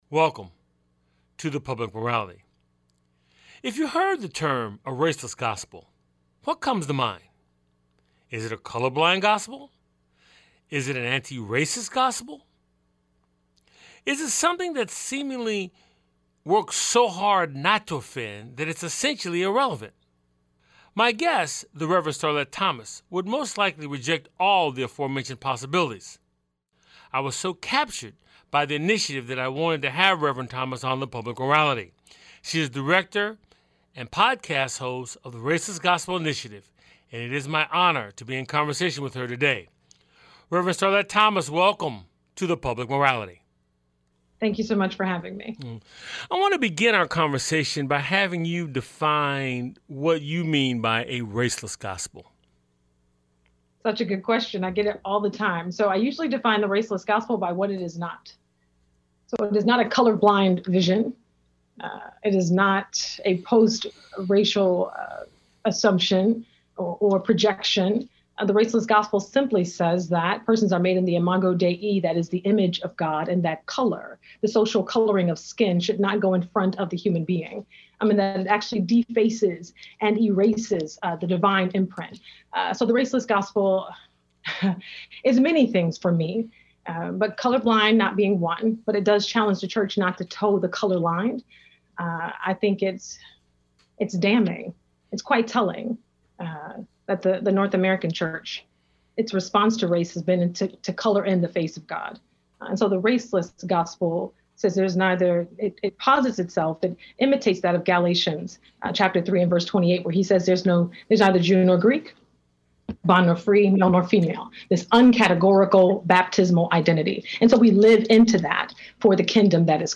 It's a weekly conversation with guest scholars, artists, activists, scientists, philosophers, and newsmakers who focus on the Declaration of Independence, the Constitution and the Emancipation Proclamation as its backdrop for dialogue on issues important to our lives.